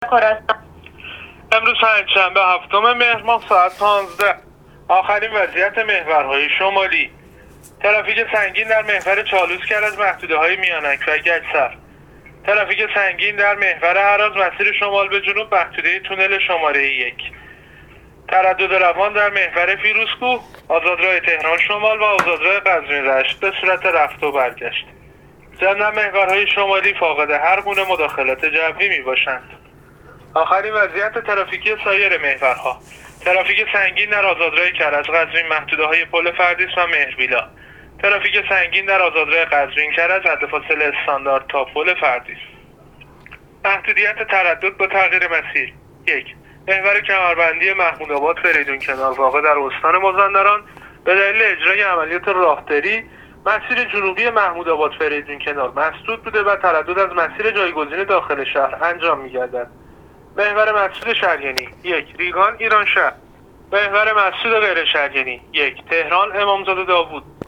گزارش رادیو اینترنتی از آخرین وضعیت ترافیکی جاده‌ها تا ساعت ۱۵ هفتم مهر؛